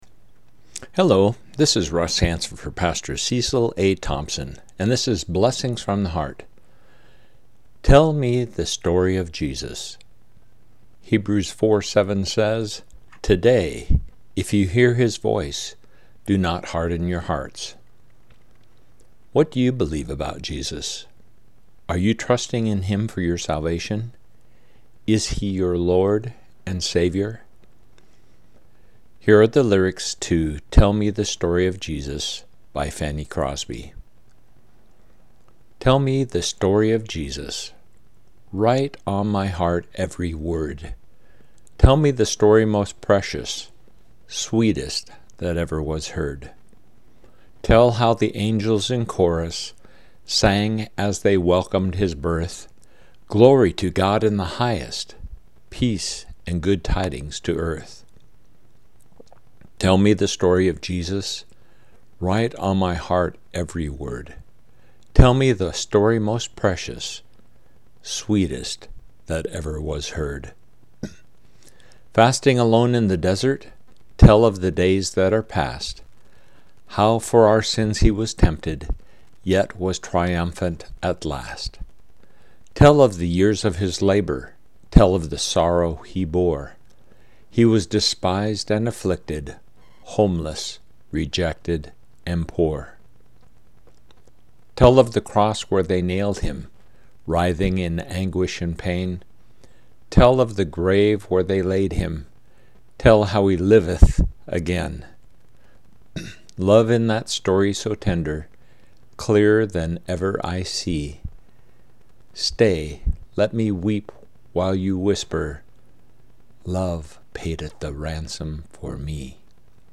Hebrews 4:7 – Devotional